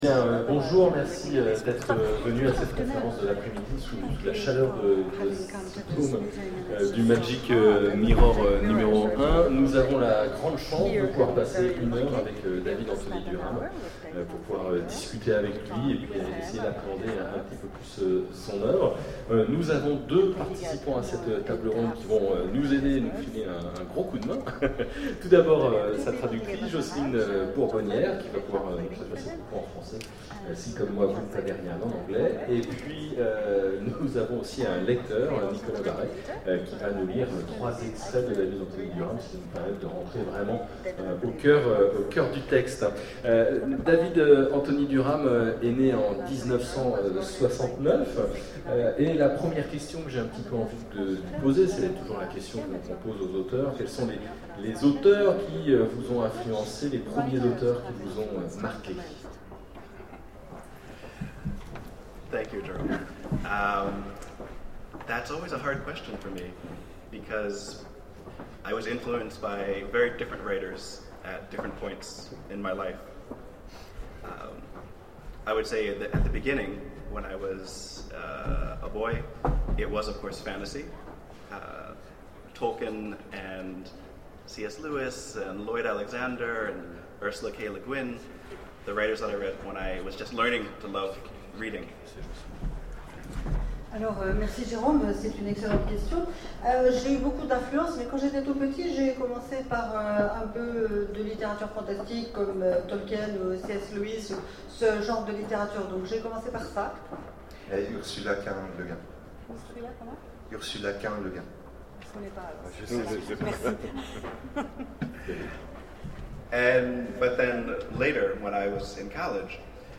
Imaginales 2012 : Conférence rencontre avec David Anthony Durham
Conférence
Rencontre avec un auteur